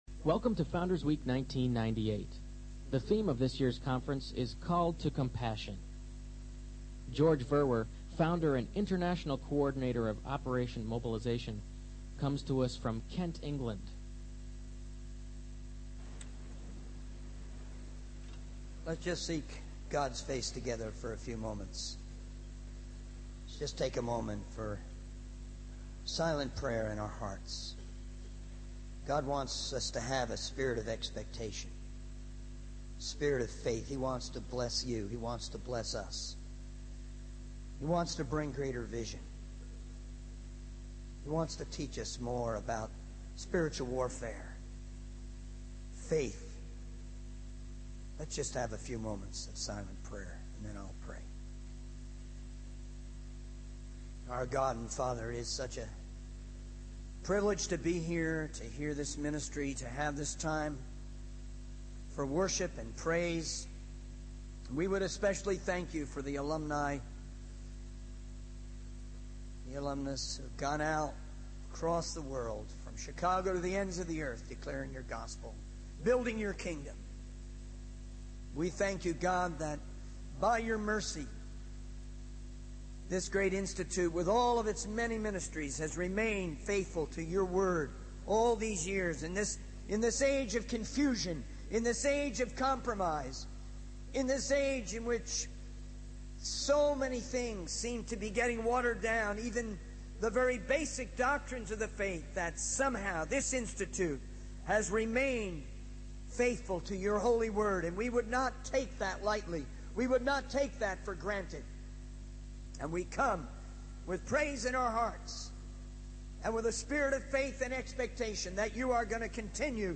In this sermon, the speaker begins by praying for an increase in faith and vision for the audience, emphasizing the importance of spreading the message of salvation to every person in the world. The speaker then shares their personal experience of being transformed by God while attending the institute.